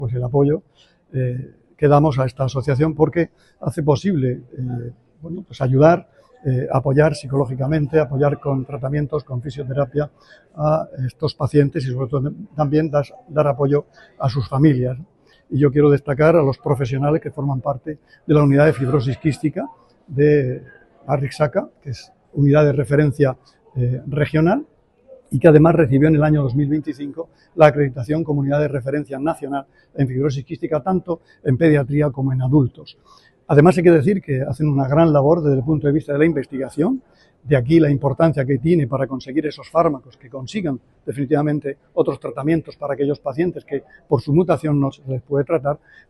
Declaraciones del consejero de Salud en el acto por el Día Mundial de la Fibrosis Quística [mp3]